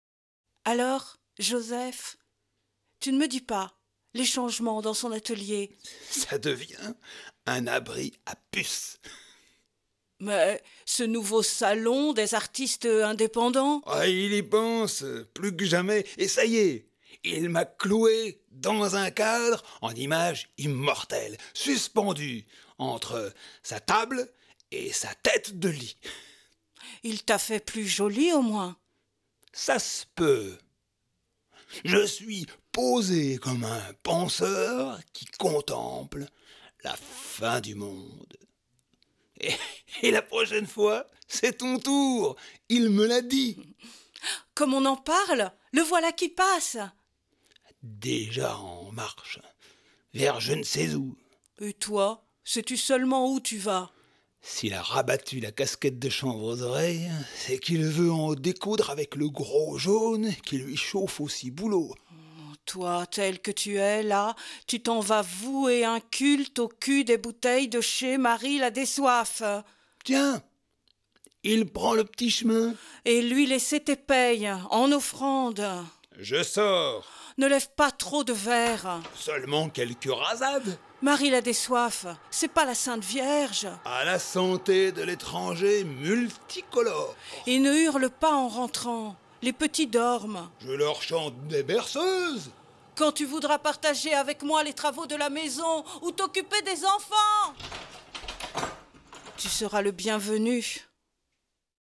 Dramatique radiophonique de la pièce Et tes soleils (50′) enregistrée en 2012 aux caves de l’atelier C à Arles